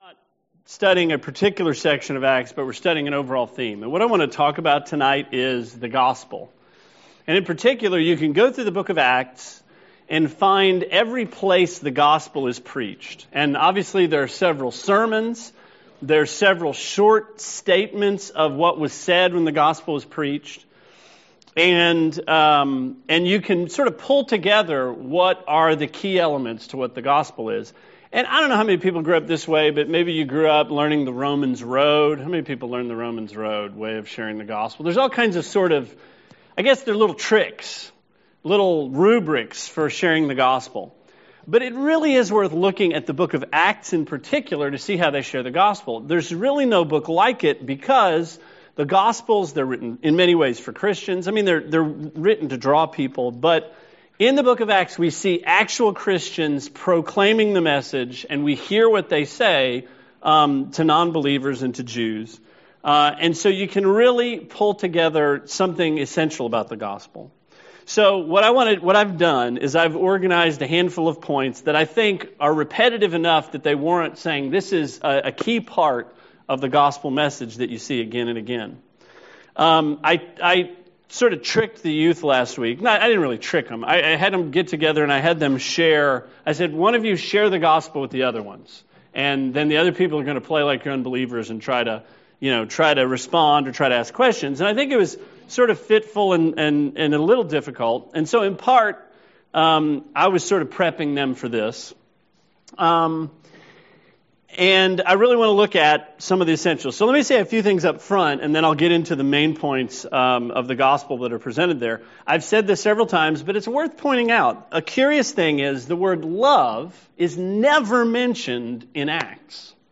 Sermon 7/29: Acts: the Gospel